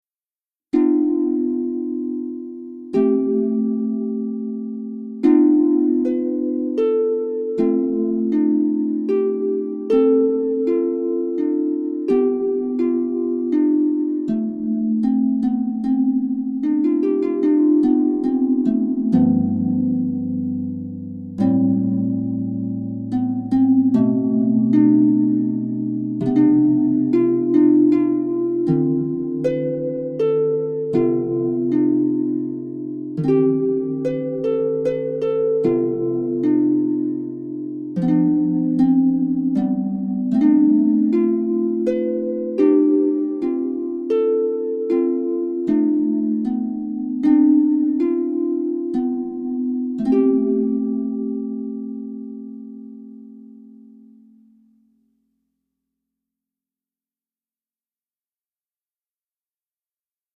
5- TO 9-TONE, OCTAVE-REPEATING SCALES